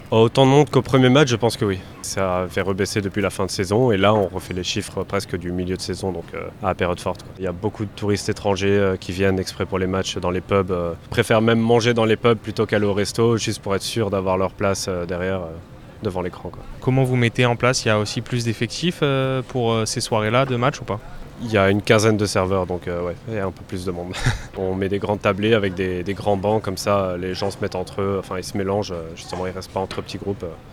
Et une fréquentation en hausse dans les restaurants et bars qui diffusent les matchs. Un surplus d'activité qui est toujours bon à prendre selon ce patron d'établissement.